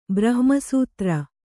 ♪ brahma sūtra